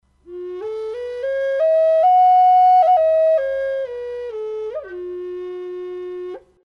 Пимак F#
Пимак F# Тональность: F#
Проста в исполнении, но имеет вполне достойное звучание.